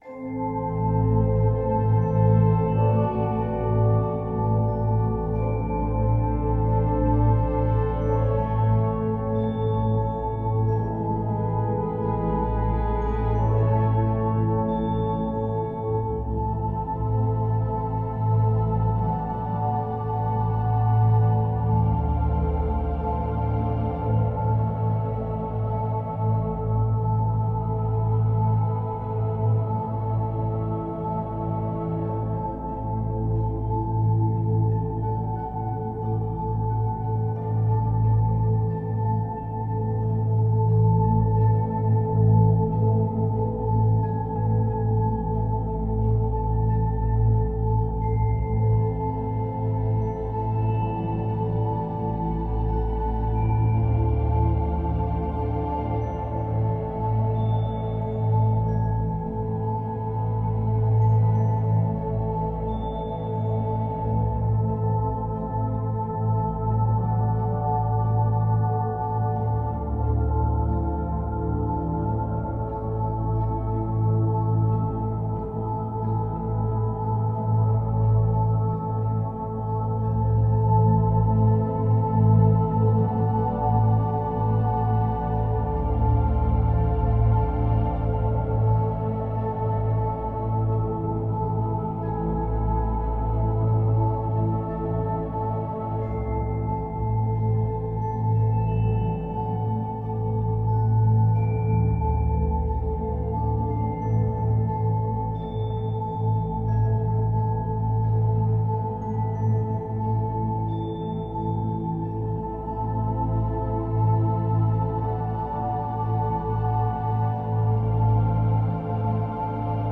La Fréquence 852Hz facilite l’amour inconditionnel
FRÉQUENCES VIBRATOIRES